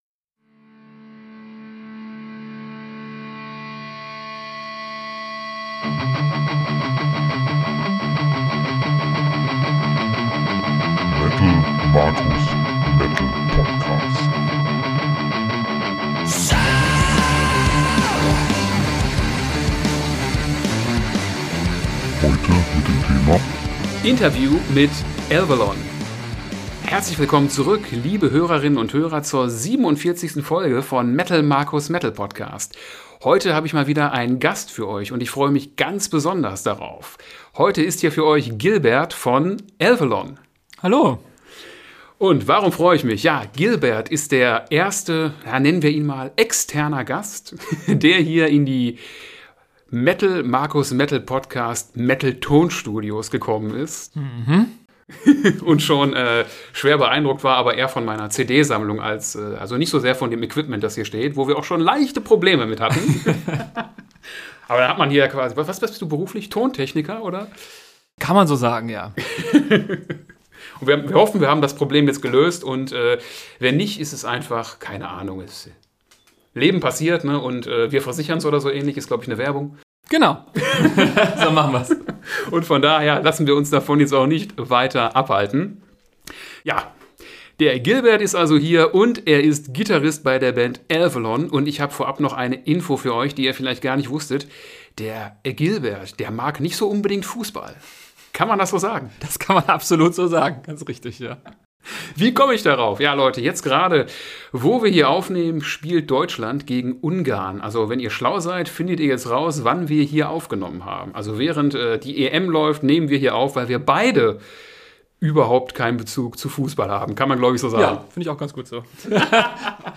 Folge 47 - Interview mit Elvellon